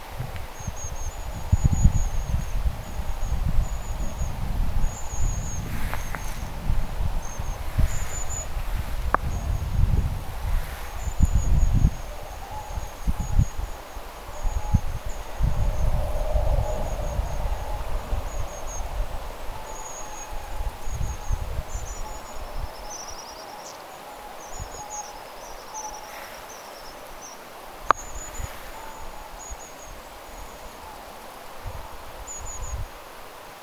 muuttava pyrstötiaisparvi, 2
muuttavia_pyrstotiaisia_2.mp3